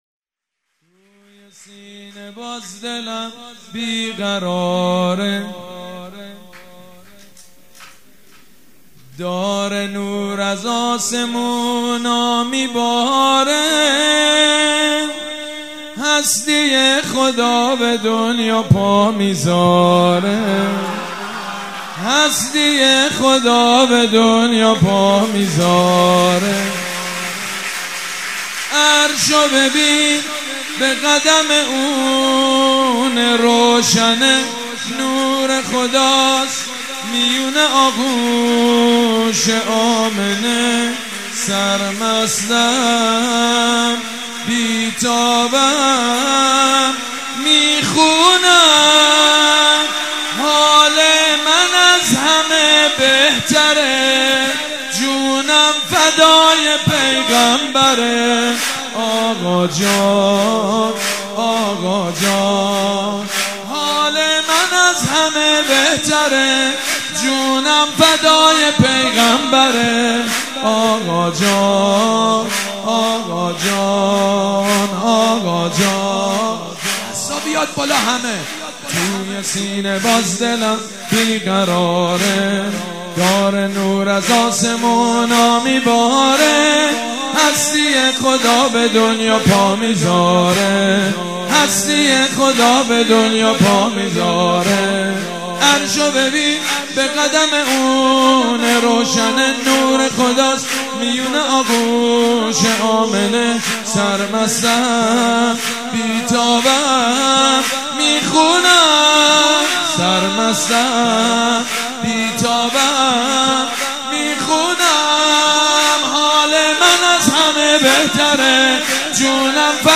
سرود
مداح
حاج سید مجید بنی فاطمه
ولادت حضرت محمد (ص) و امام صادق (ع)